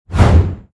CHQ_VP_swipe.mp3